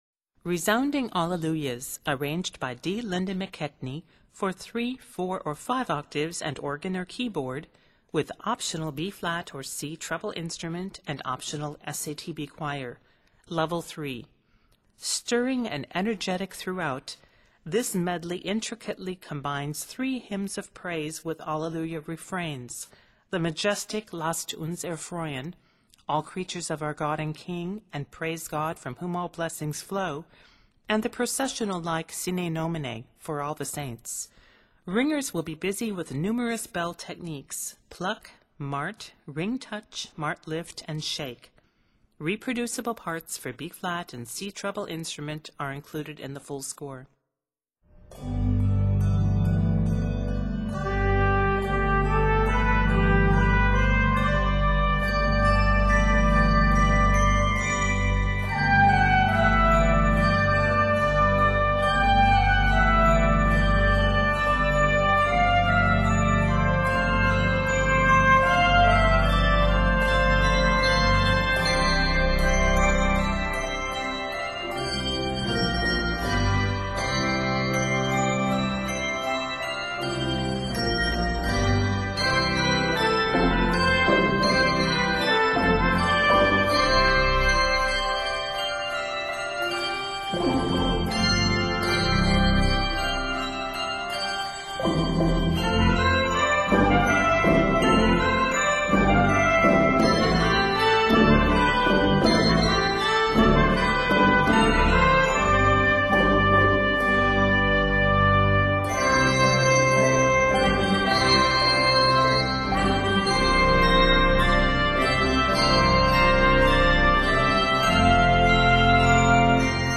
Stirring and energetic throughout
Octaves: 3-5